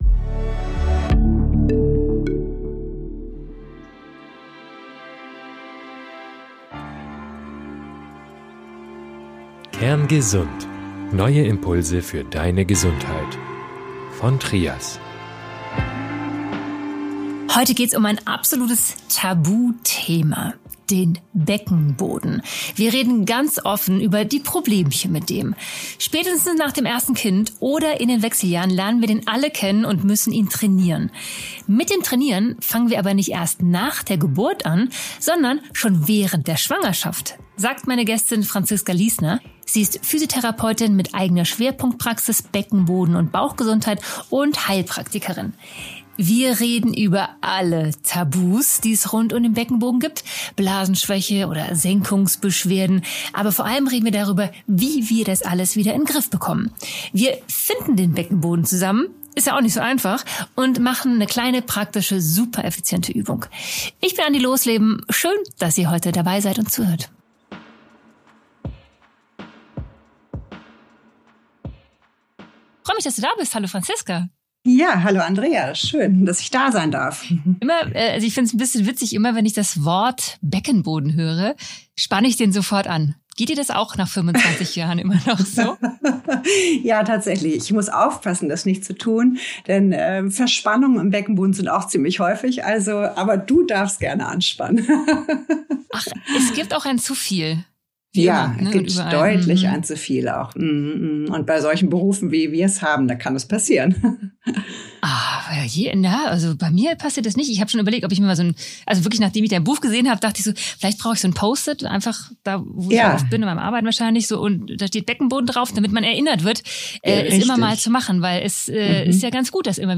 Ein spannendes Interview für alle, die mehr über ihren Körper erfahren und ihre Gesundheit verbessern möchten.